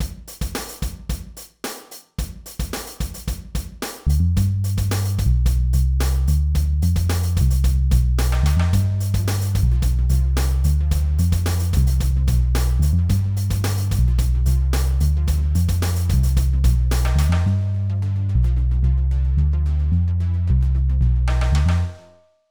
Cortinilla musical de paso al espacio del tiempo
Sonidos: Música